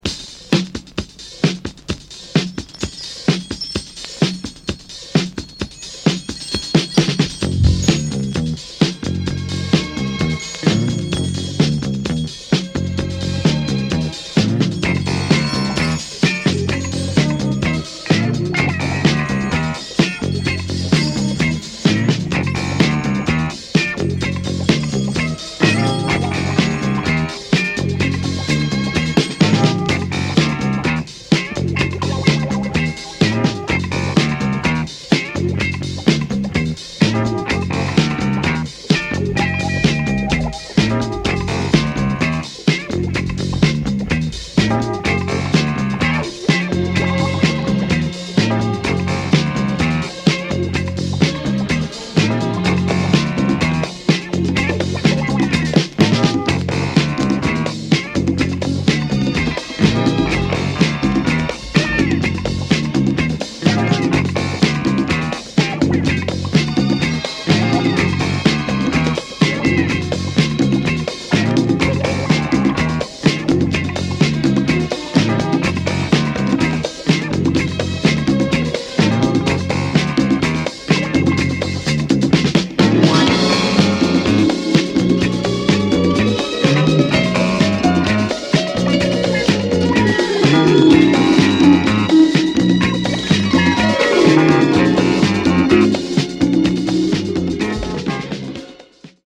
Obscure UK Disco, it’s all about the 6 minute inst.